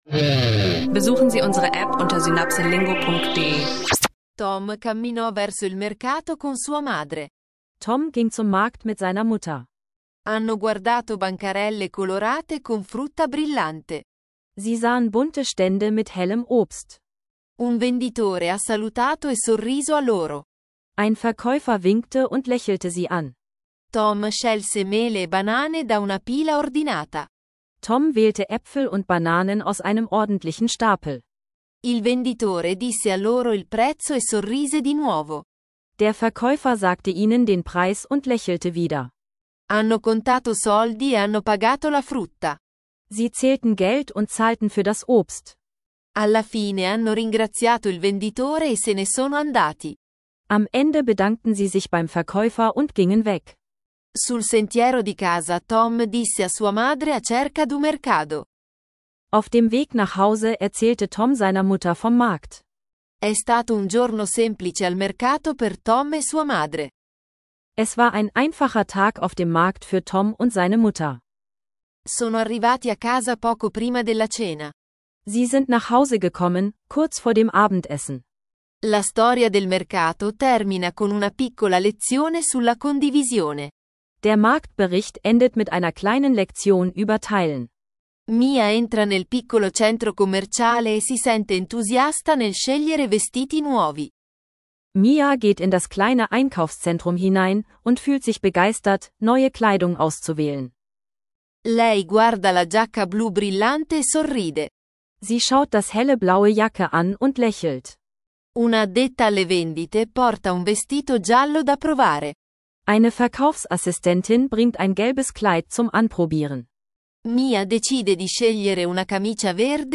Drei kurze Geschichten und Dialoge für Anfänger: Markt, Shopping-Outfits und europäische Fußballqualifikation – Italienisch lernen mit SynapseLingo.